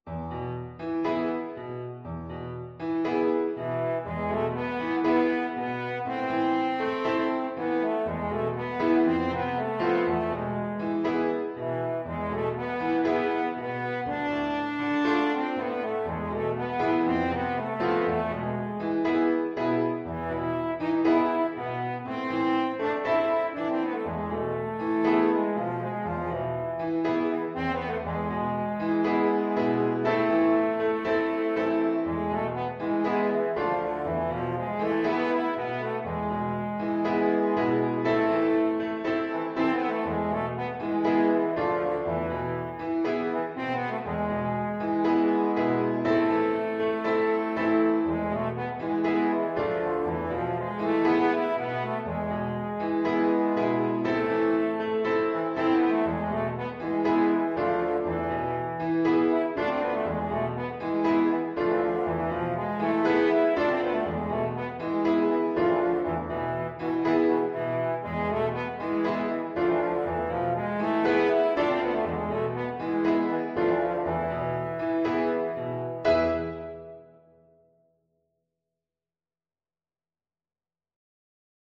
French Horn
Traditional Music of unknown author.
A minor (Sounding Pitch) E minor (French Horn in F) (View more A minor Music for French Horn )
Allegro moderato =120 (View more music marked Allegro)
4/4 (View more 4/4 Music)